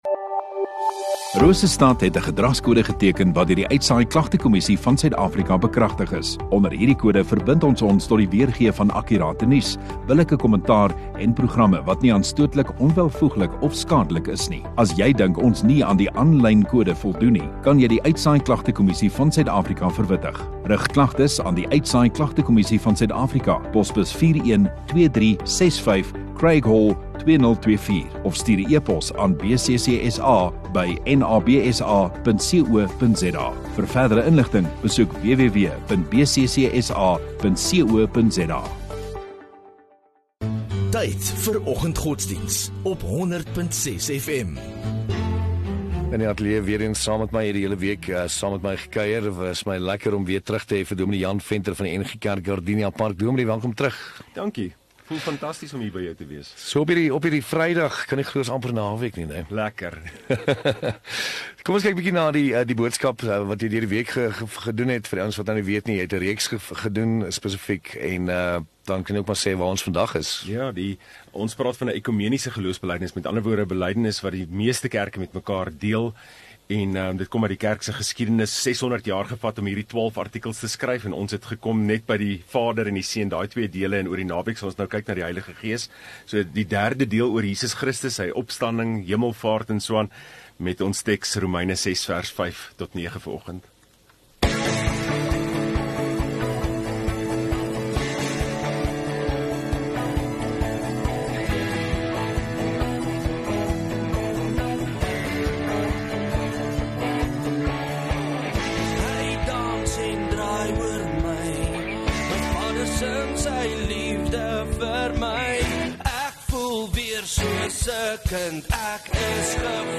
3 Nov Vrydag Oggenddiens